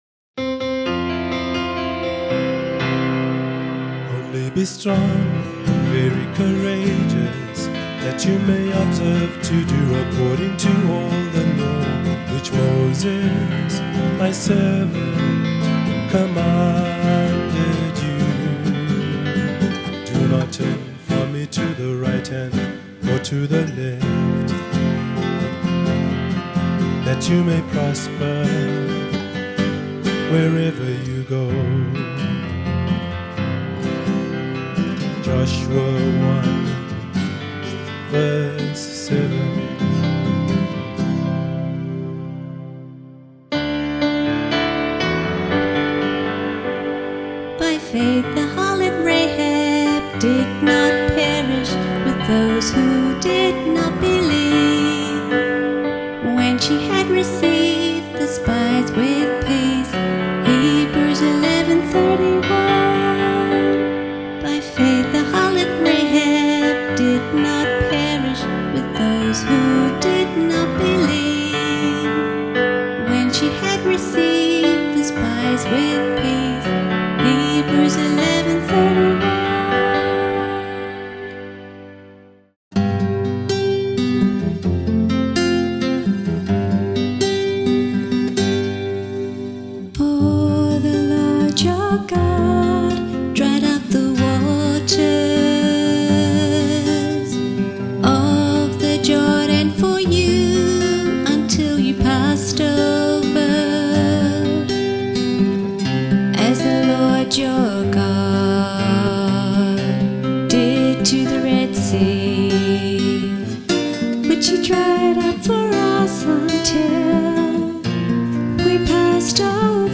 Vocalist
Guitar